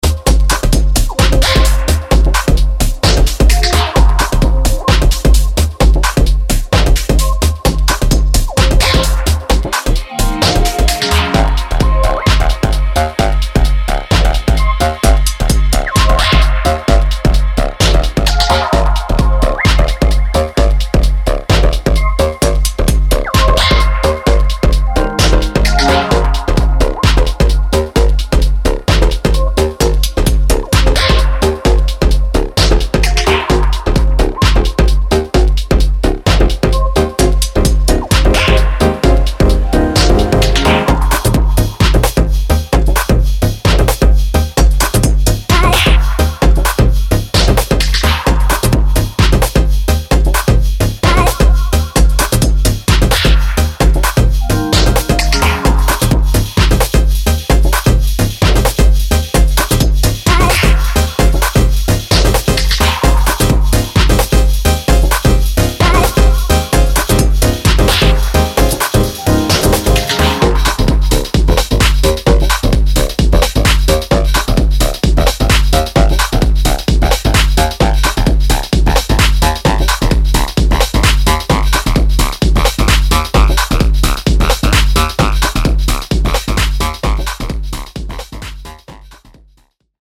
dynamic, experimental electronica